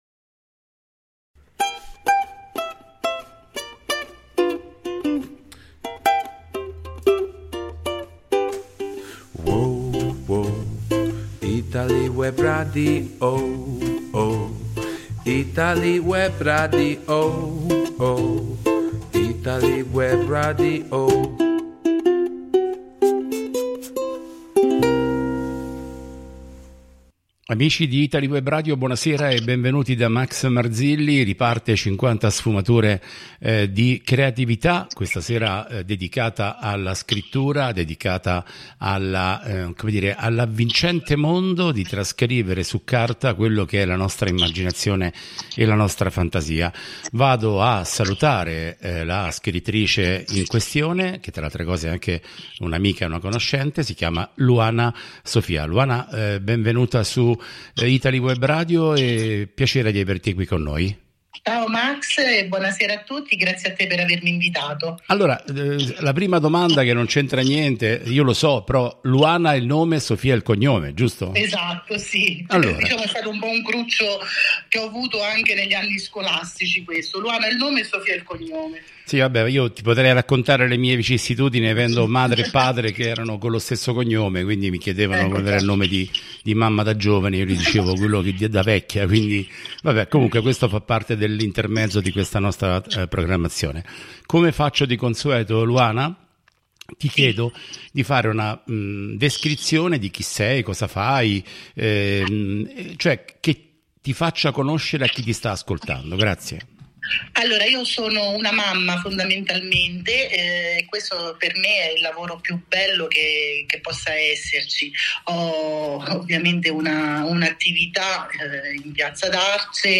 È stata molto più di una semplice intervista: un dialogo vero, sincero, in cui ho potuto condividere non solo la storia, ma anche ciò che c'è dietro ogni pagina, ogni emozione, ogni scelta.